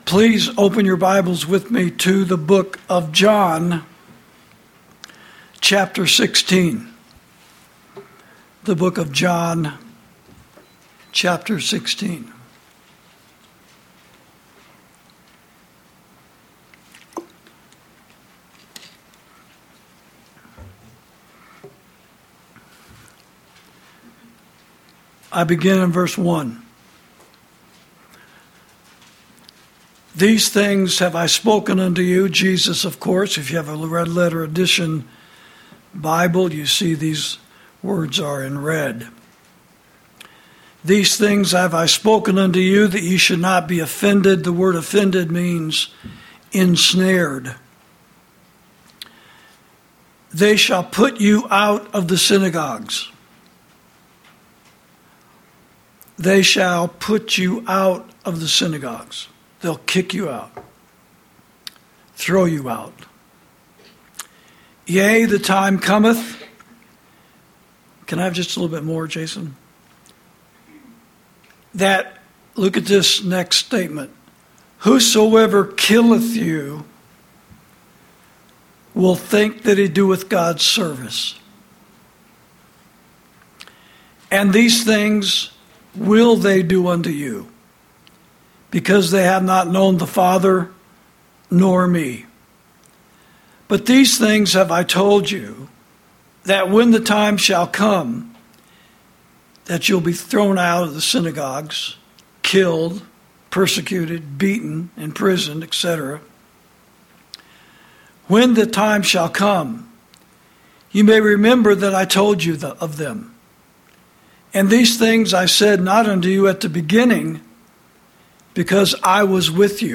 Sermons > No King But Jesus!